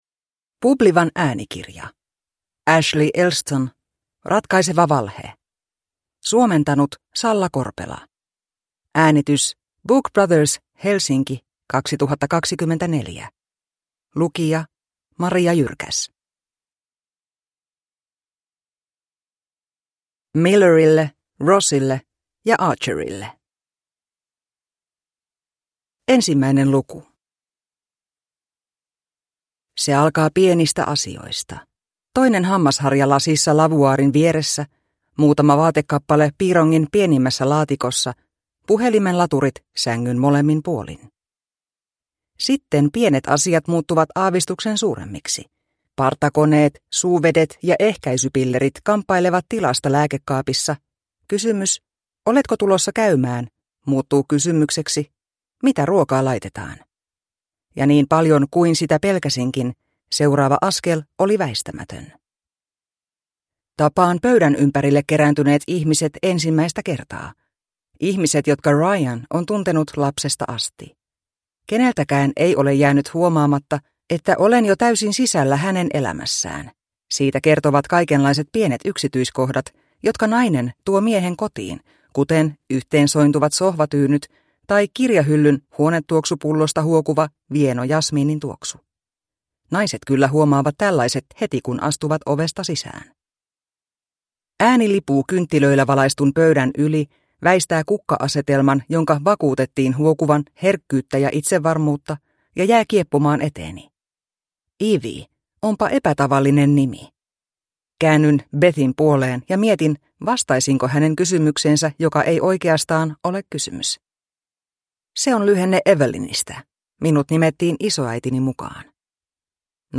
Ratkaiseva valhe (ljudbok) av Ashley Elston